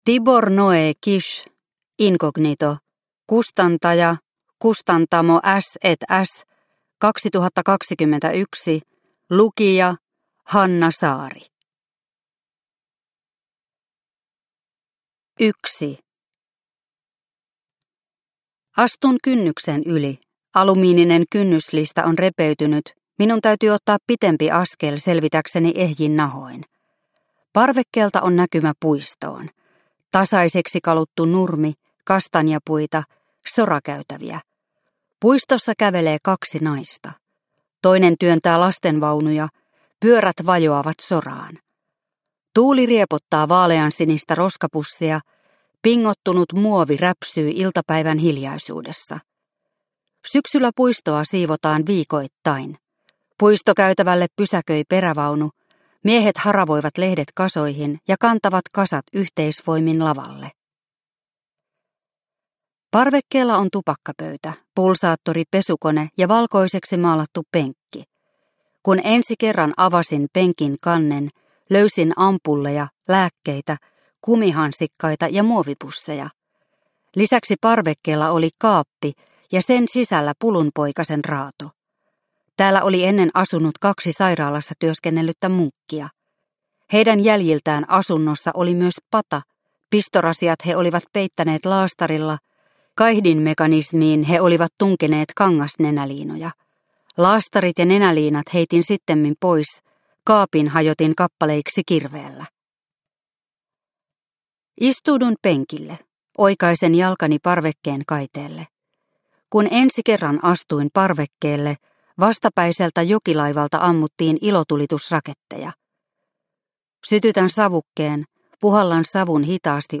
Inkognito – Ljudbok – Laddas ner